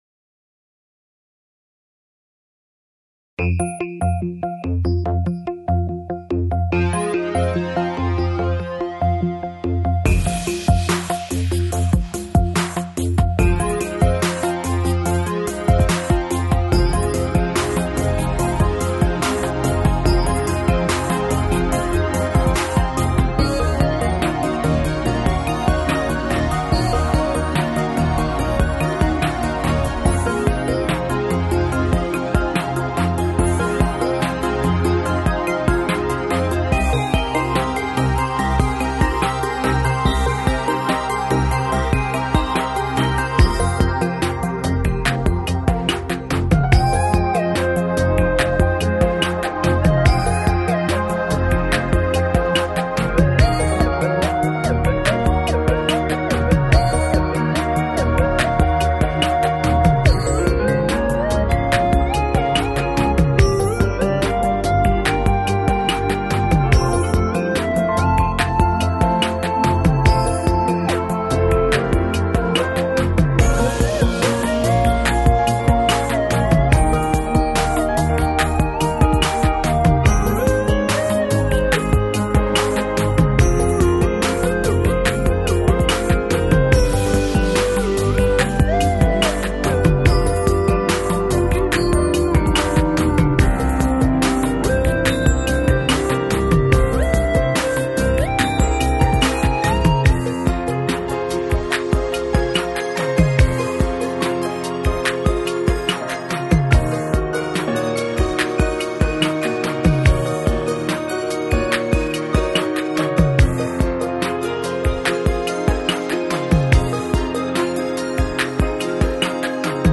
Жанр: Downtempo, Lounge, Chill Out